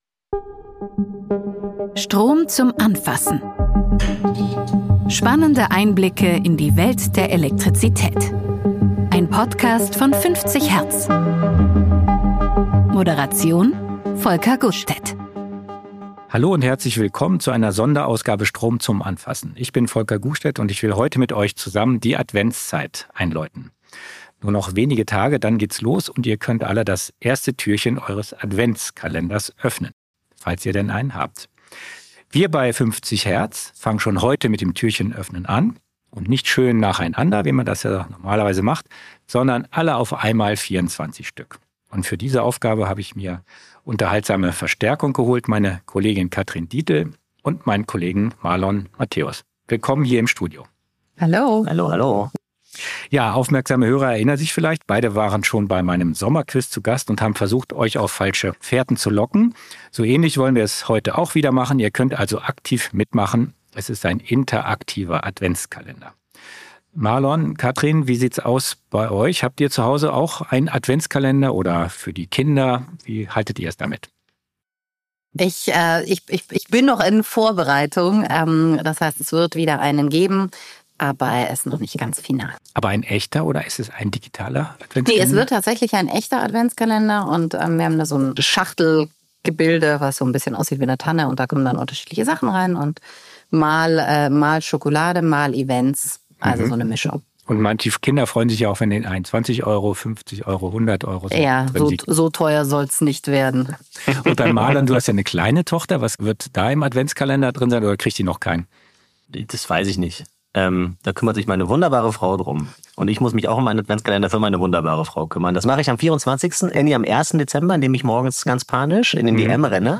Es darf wieder mitgeraten werden. Ist falsch oder richtig, was sich hinter den Türen unseres akustischen Adventskalenders verbirgt?